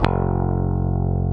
Index of /90_sSampleCDs/Roland - Rhythm Section/GTR_Distorted 1/GTR_Process Lead
GTR ELGTR 00.wav